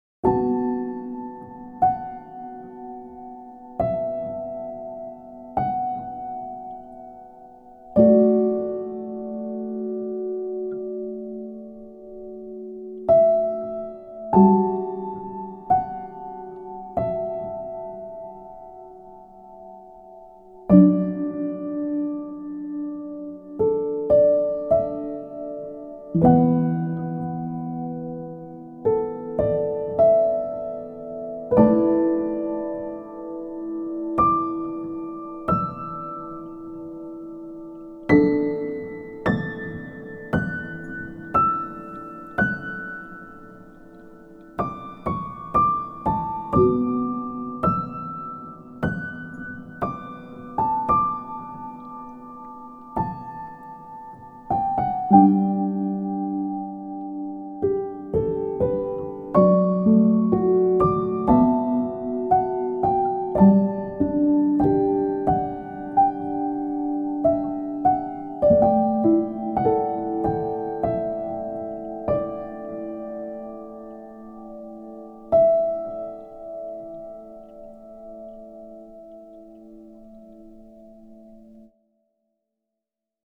ピアノ 寝落ち 穏やか